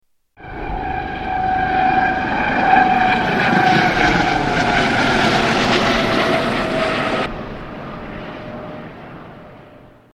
NYC Heliport
Tags: New York New York city New York city sounds NYC Travel